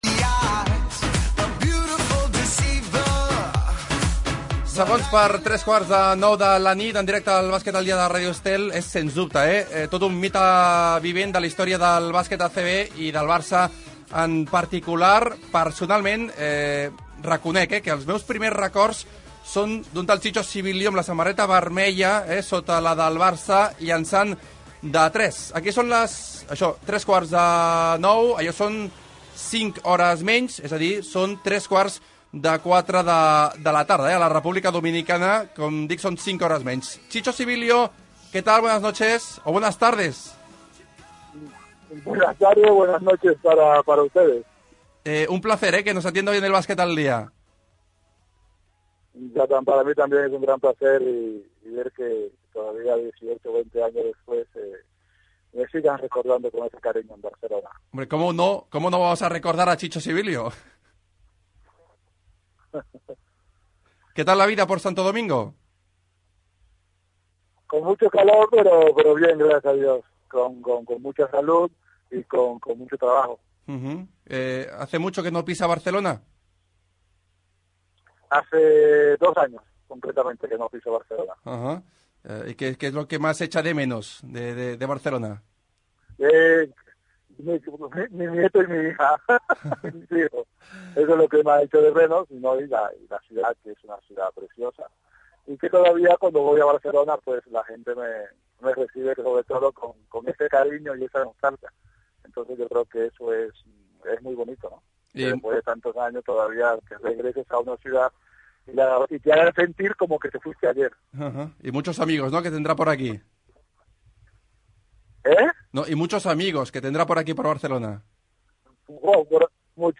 Entrevista a Chicho Sibilio 2014